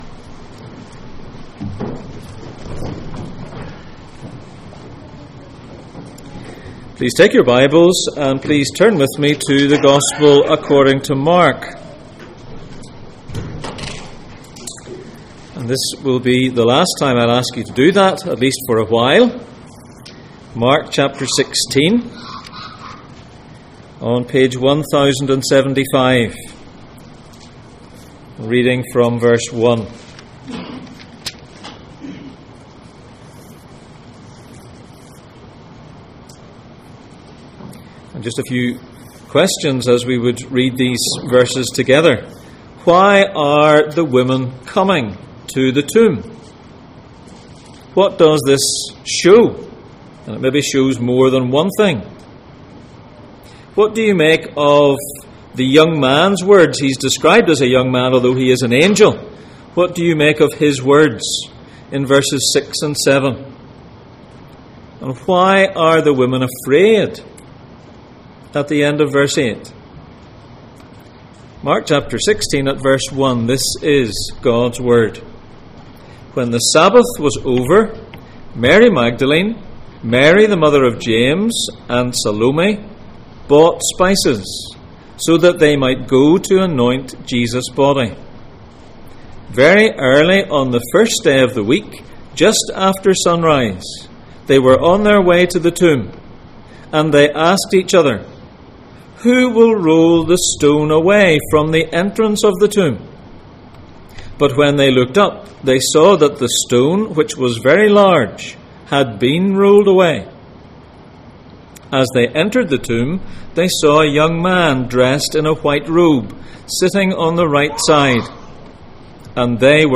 Jesus in Mark Passage: Mark 16:1-8 Service Type: Sunday Morning %todo_render% « A Message to the World Attention